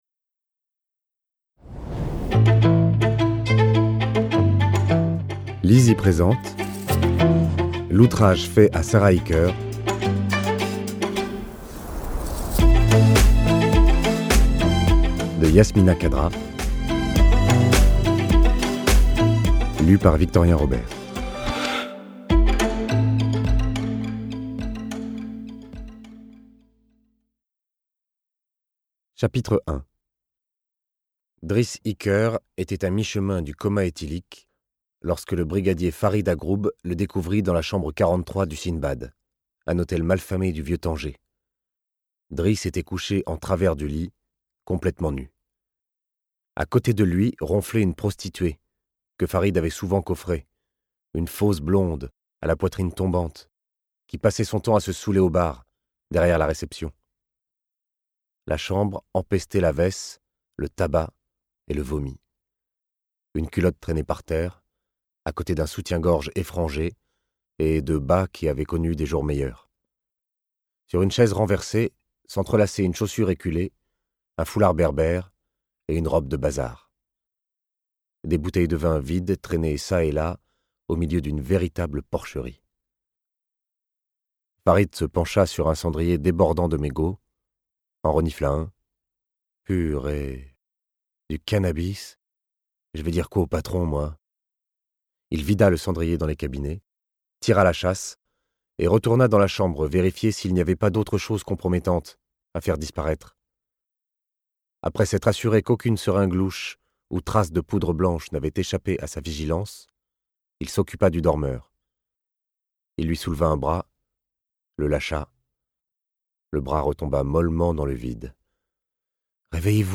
Click for an excerpt - L'outrage fait à Sarah Ikker de Yasmina Khadra